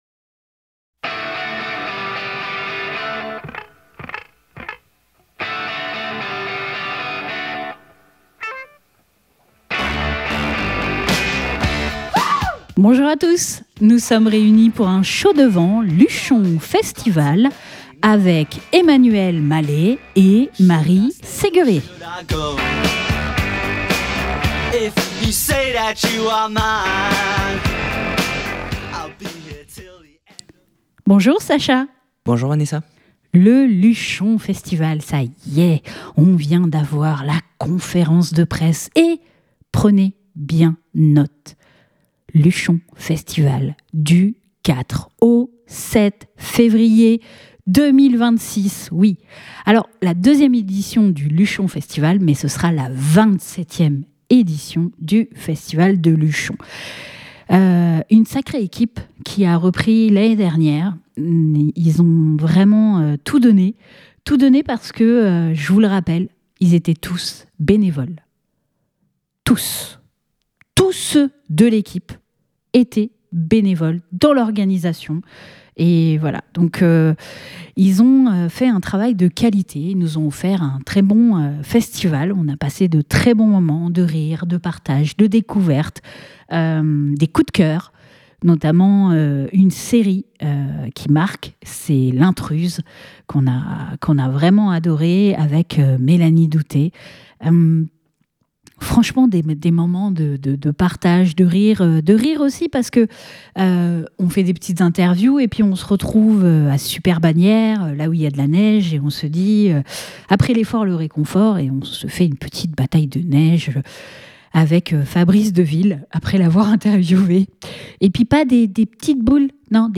Le 13 janvier nous avons assisté à la conférence de presse du Luchon Festival qui aura lieu du 04 au 07 février dans moins d'un mois.